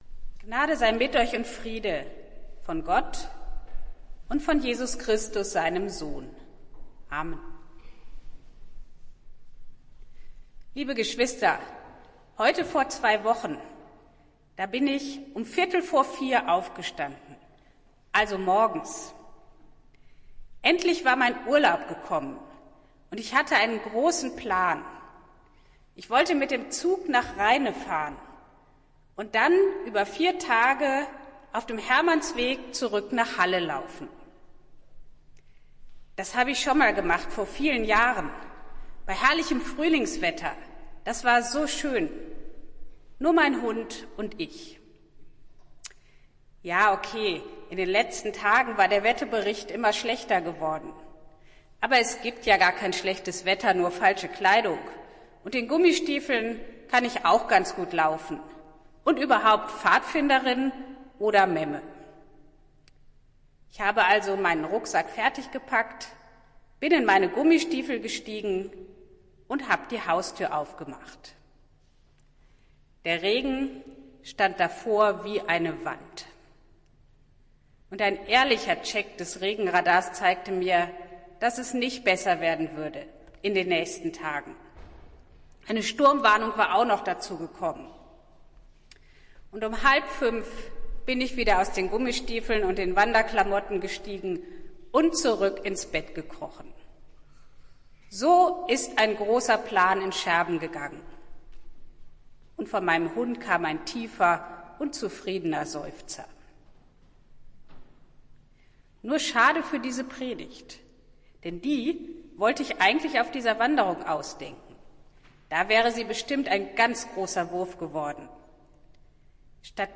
Predigt des Gottesdienstes aus der Zionskirche vom Karfreitag, 07.04.2023, 10:00 Uhr